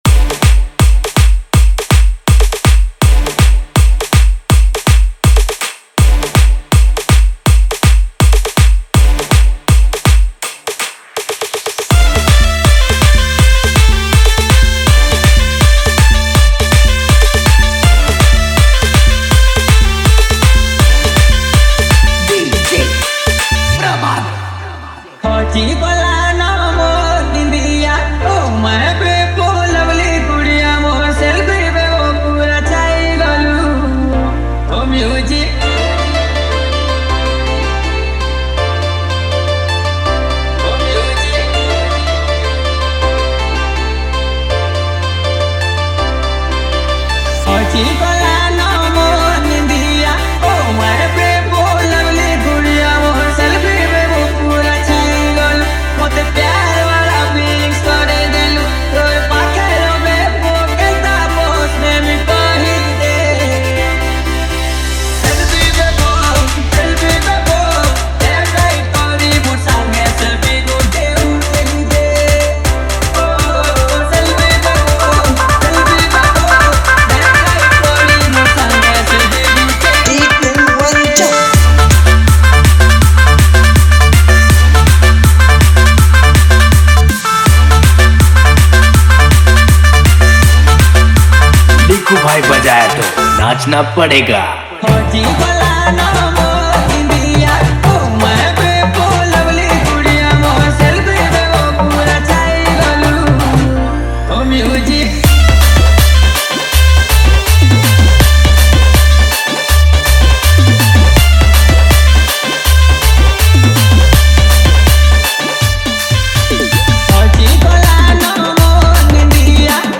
Category:  Sambalpuri Dj Song 2025